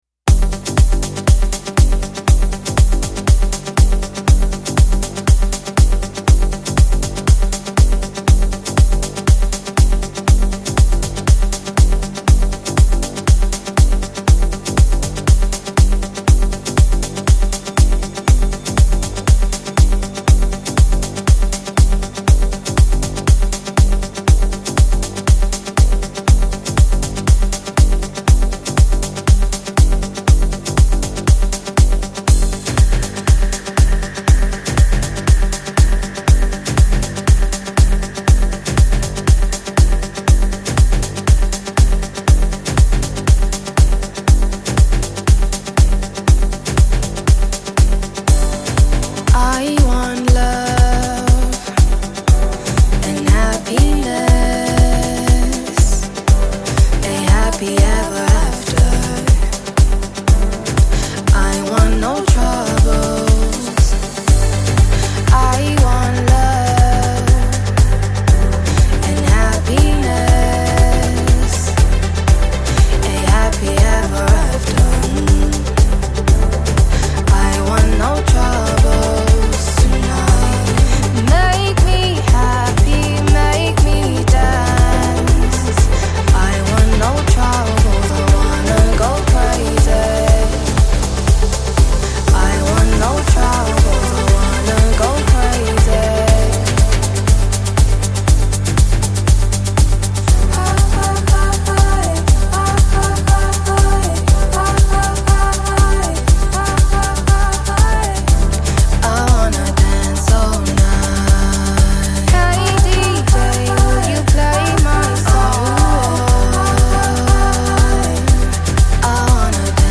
• Gênero: Pop